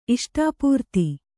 ♪ iṣṭāpūrti